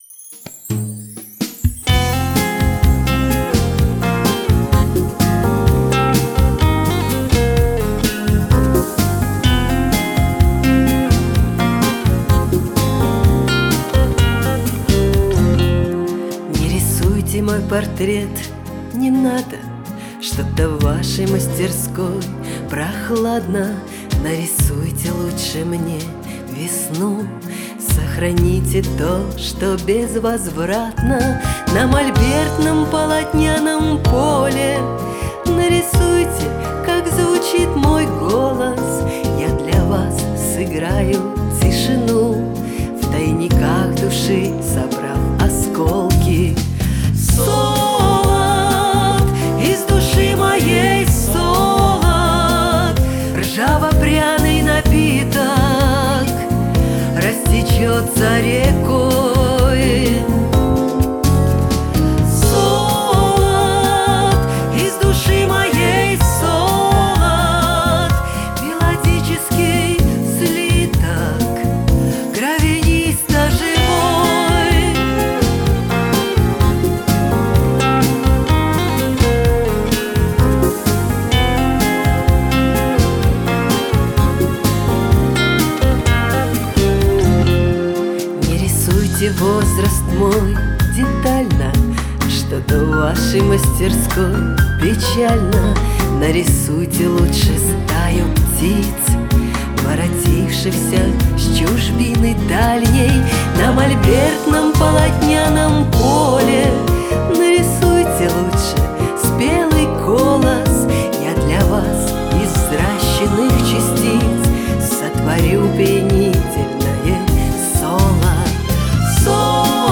вокал
электрогитара
акустическая гитара, бэк-вокал